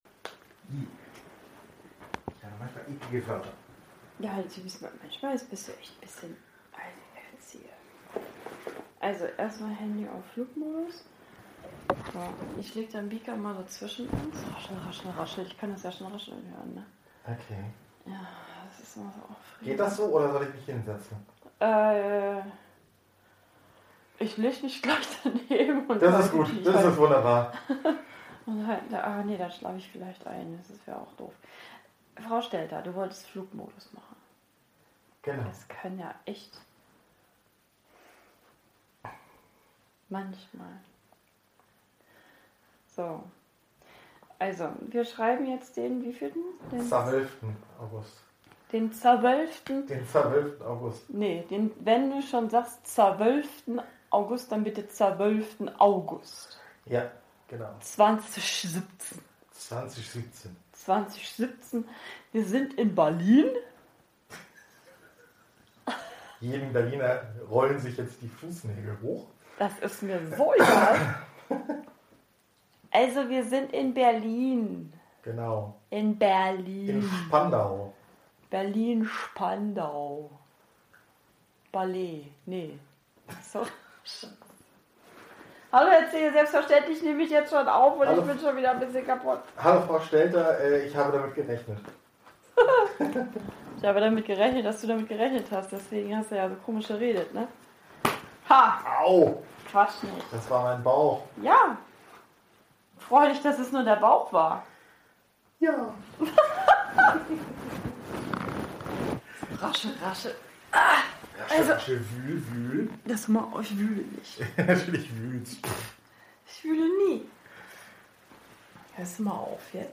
(Ja, ich weiß, fast eine Stunde Gesabbel und dann auch noch ohne Kapitelmarken, aber immerhin pupsen wir auch mittendrin.